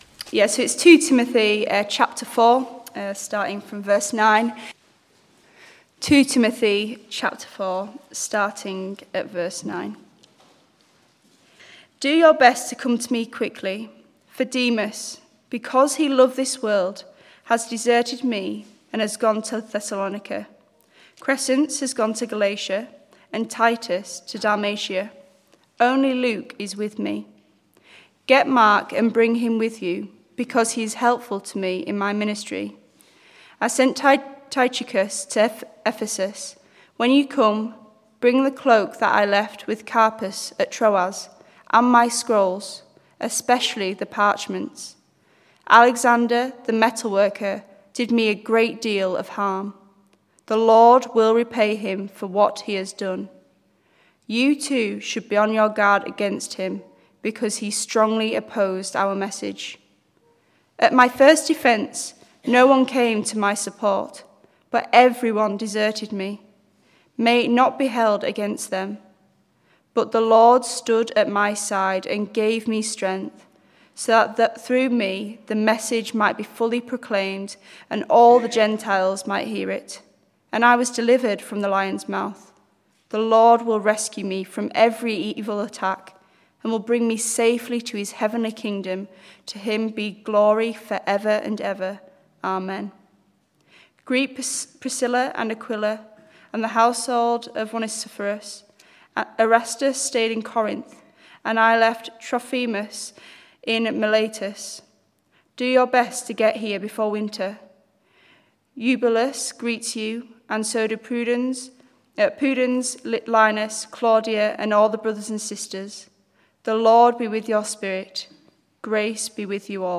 Service Type: Morning Service 9:15